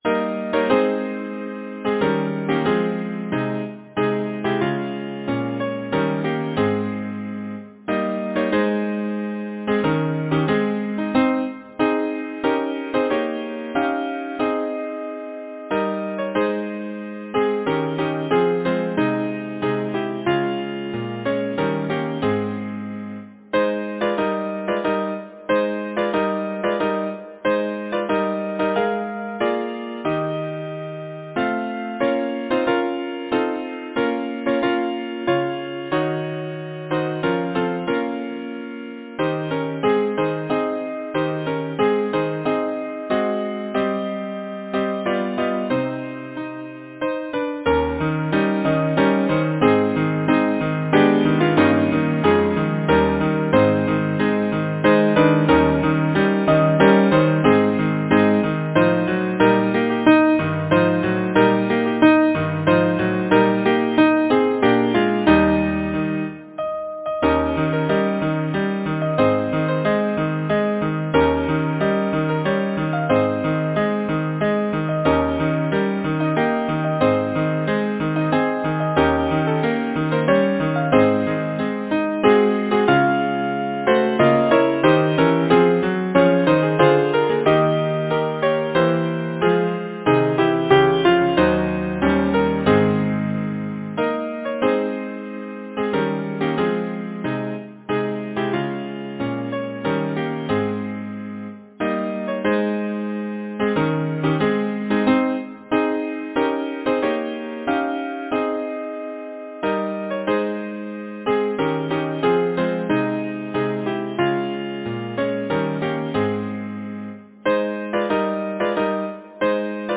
Title: The chimes of Oberwesel Composer: Henry Baumer Lyricist: Andrew B. Picken Number of voices: 4vv Voicing: SATB, some B divisi Genre: Secular, Partsong
Language: English Instruments: Keyboard